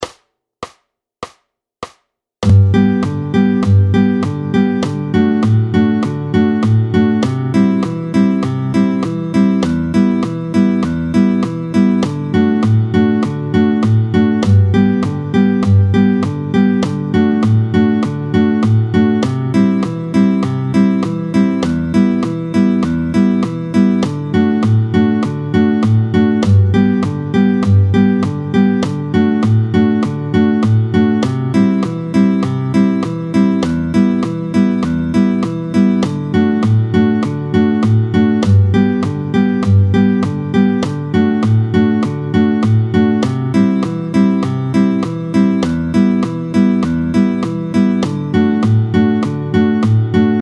07-01 La mesure à 4/4. Vite 100